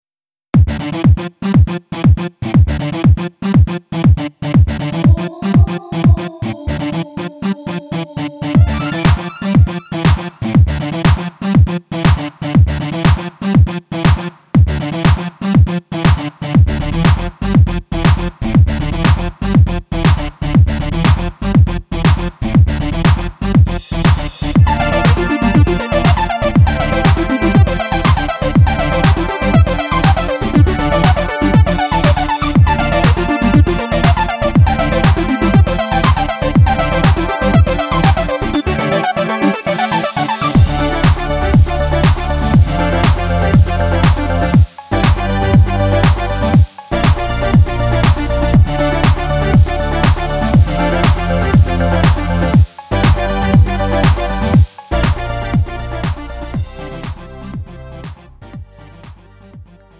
장르 가요 구분 Lite MR